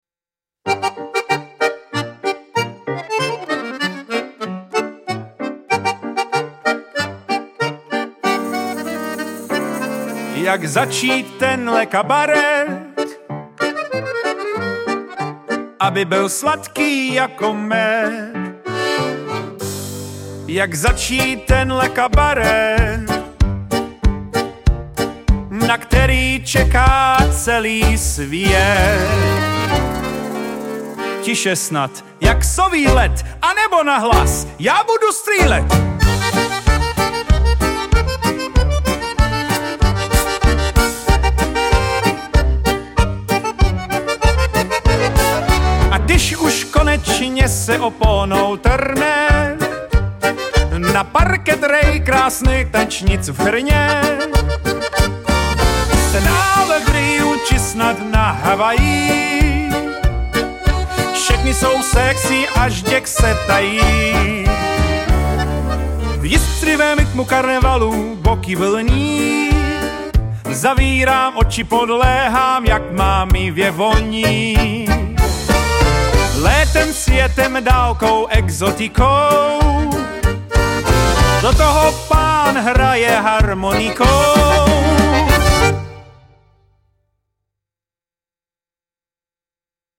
Storytelling, freestyle with Theater atmosphere in Kabaret style.
Generated track